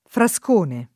frascone [ f ra S k 1 ne ] s. m.